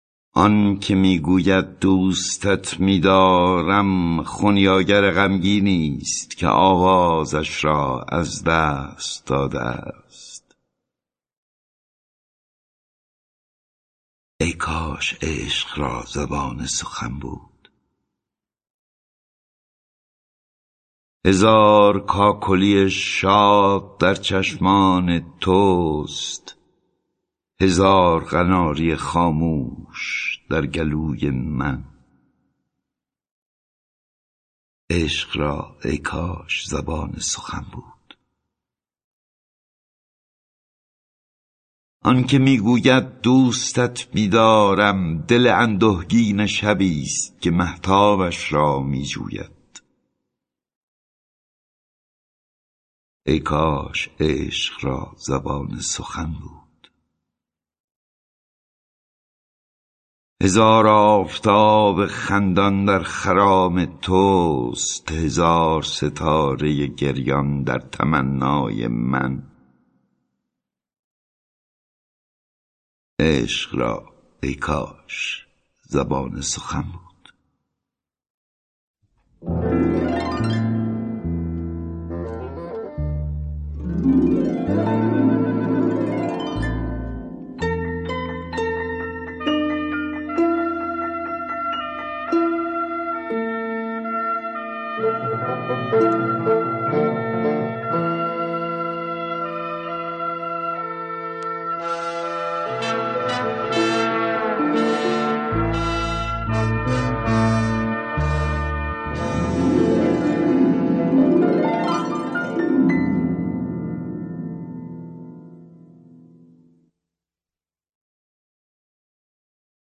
دکلمه شعر عاشقانه (آن که می گوید دوست ات می دارم…) با صدای احمد شاملو